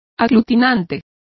Complete with pronunciation of the translation of adhesive.